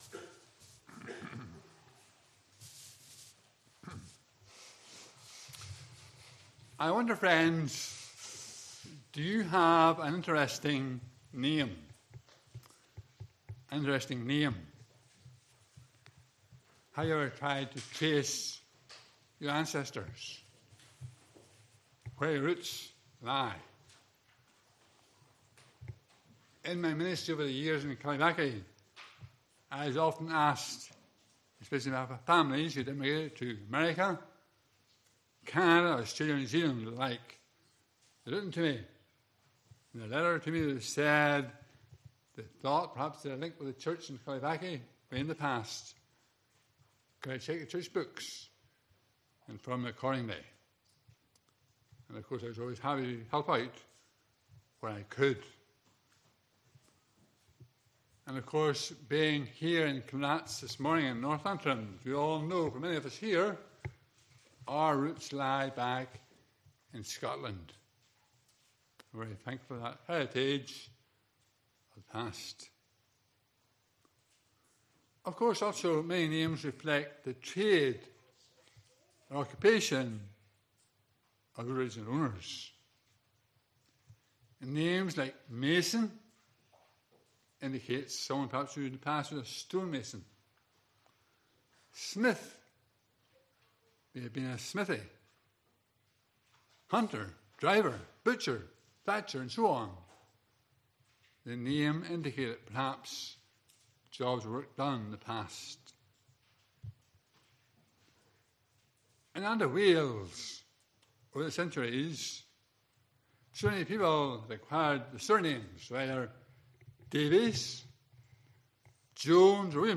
Passage: Matthew 1:18-25 Service Type: Morning Service